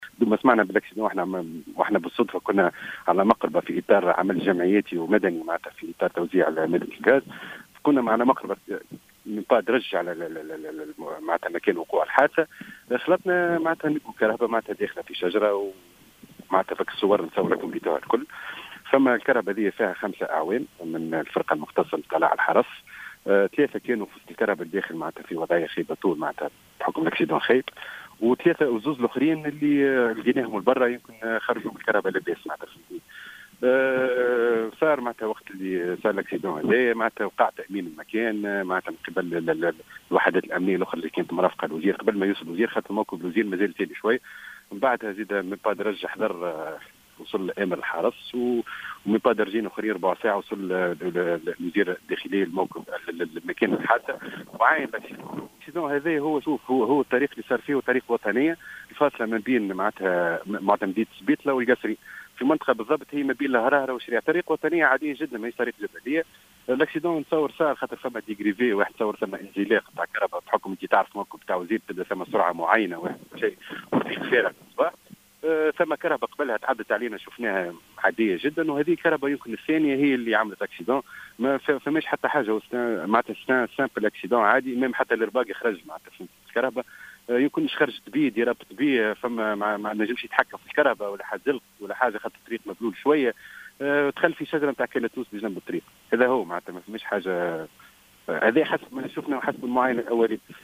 حادث مرور يودي بحياة 3 أعوان حرس: شاهد عيان يروي التفاصيل